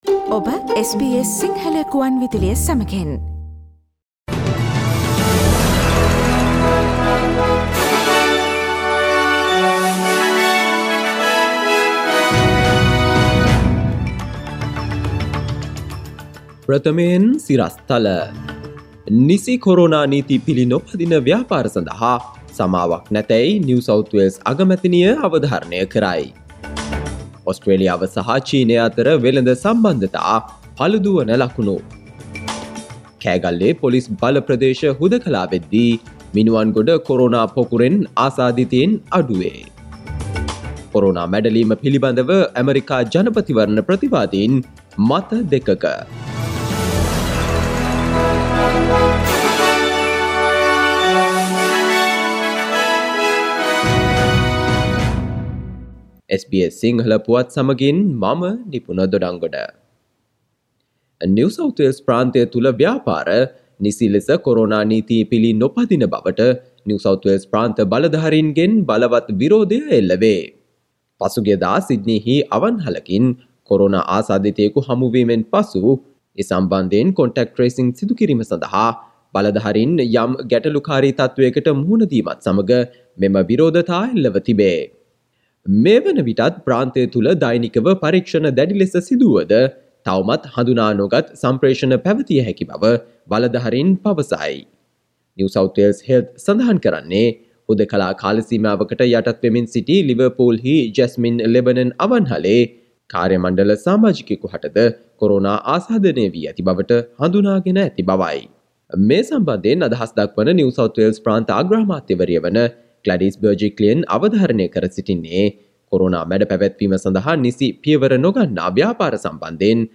Daily News bulletin of SBS Sinhala Service: Tuesday 03 November 2020